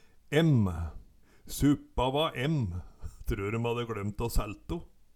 emm - Numedalsmål (en-US)